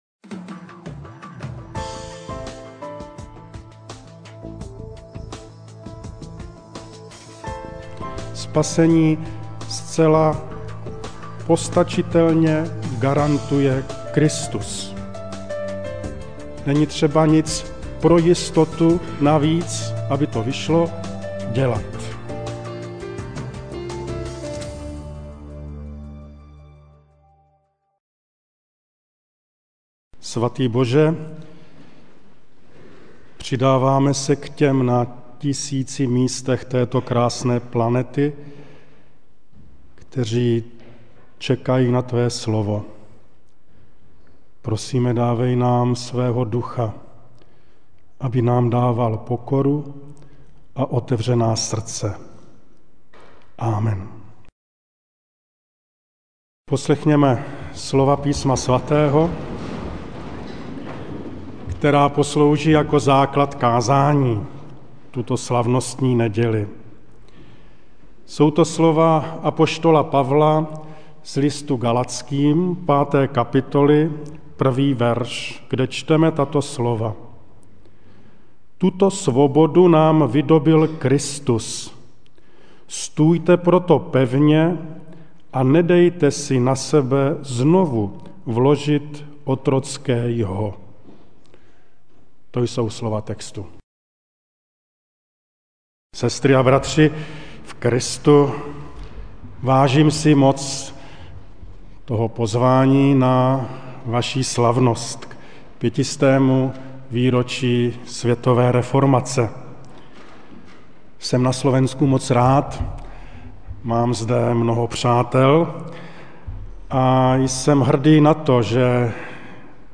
MP3 SUBSCRIBE on iTunes(Podcast) Notes Sermons in this Series Ranná kázeň: Oslobodení Božou milosťou! (Gal. 5, 1) Kristus nás oslobodil k slobode.